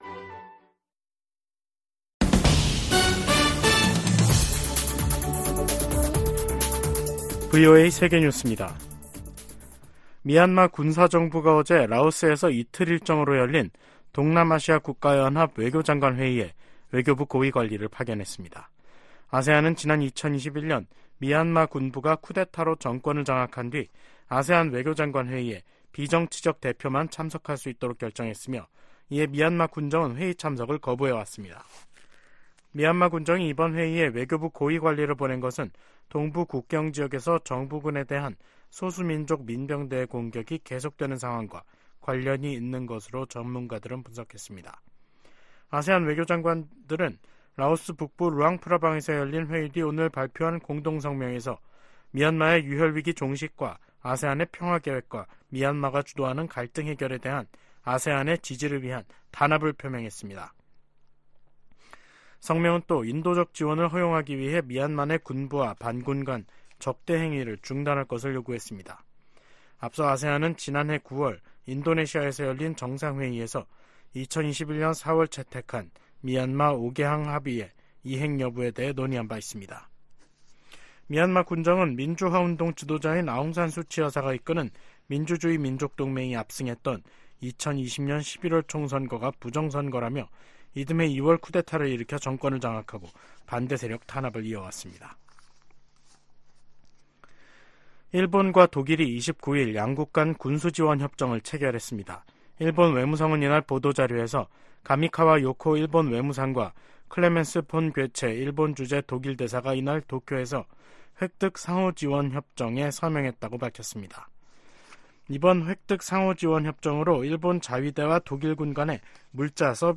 세계 뉴스와 함께 미국의 모든 것을 소개하는 '생방송 여기는 워싱턴입니다', 2024년 1월 29일 저녁 방송입니다. '지구촌 오늘'에서는 요르단 기지에 대한 드론(무인항공기) 공격으로 미군 3명이 숨진 소식 전해드리고, '아메리카 나우'에서는 공화당이 남부 국경관리 실패책임을 물어 알레한드로 마요르카스 국토안보부 장관 탄핵 소추안을 발의한 이야기 살펴보겠습니다.